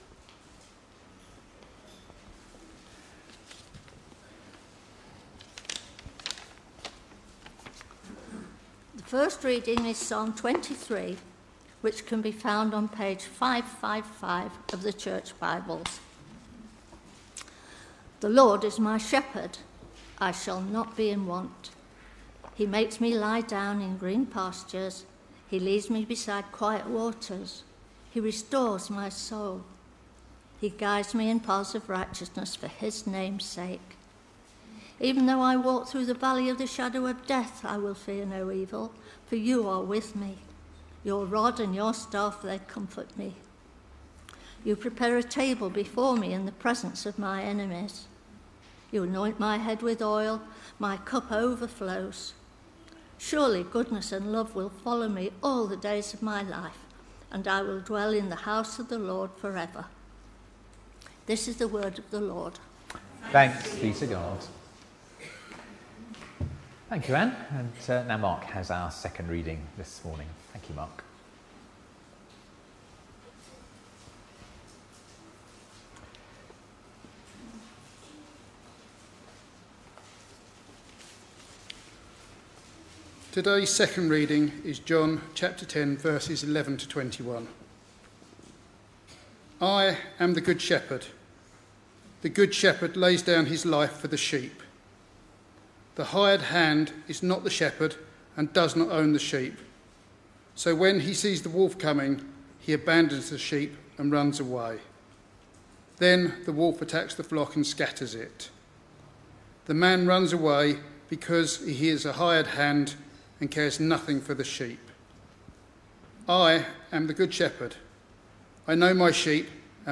Readings-and-Sermon-for-6th-July-2025.mp3